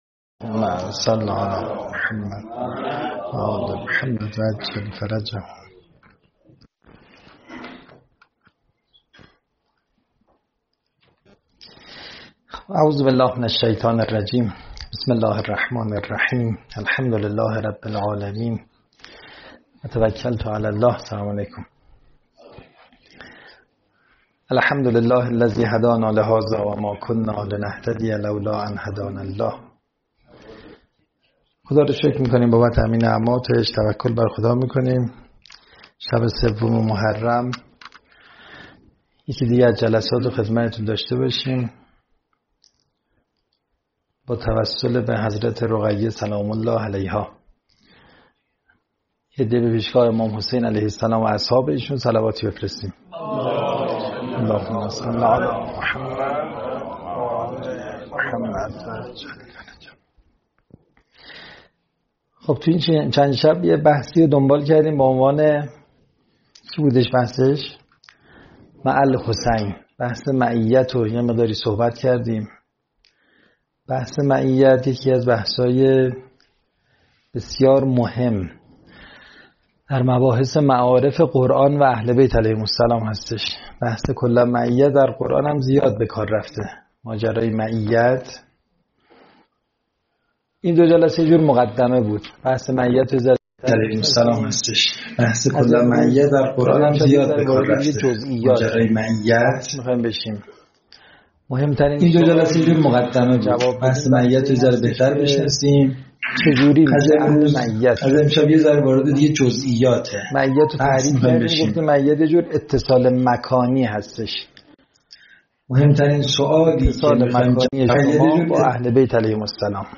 سخنرانی
محرم 1404 مع الحسین علیه السلام